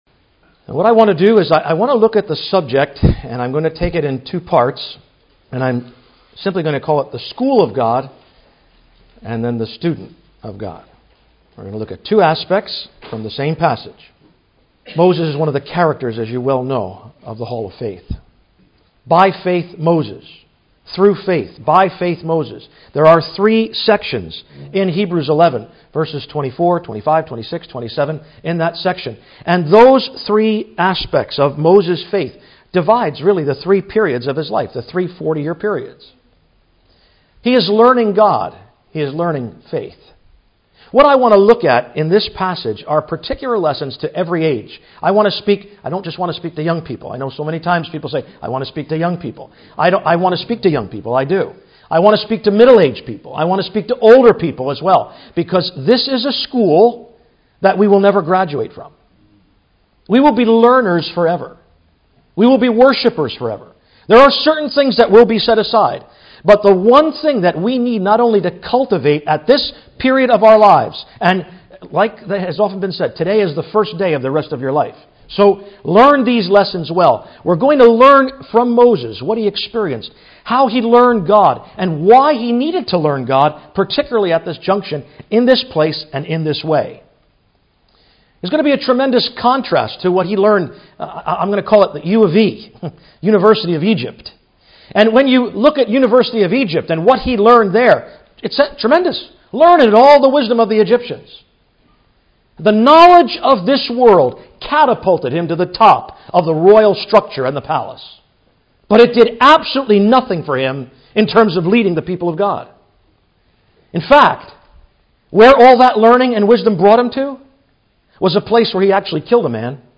2012 Easter Conference